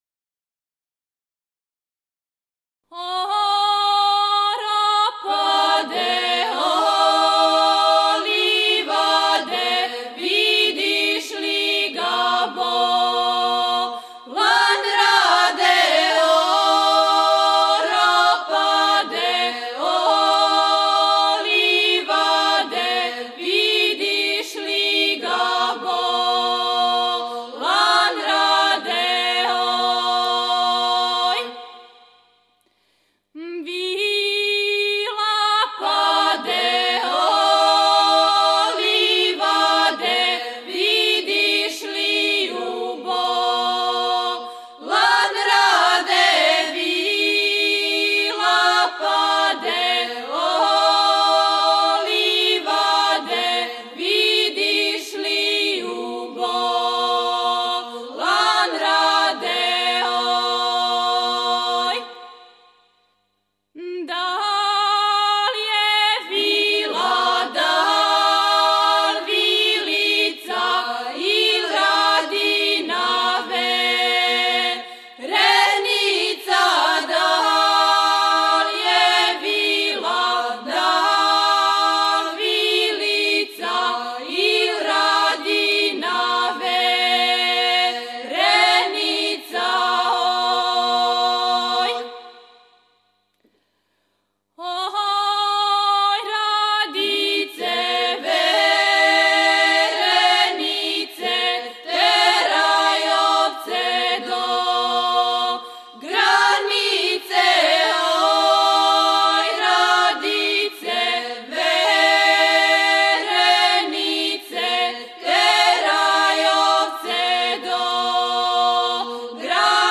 Порекло песме: Село Мужинац, Сокобања Начин певања: На бас. Напомена: Љубавна песма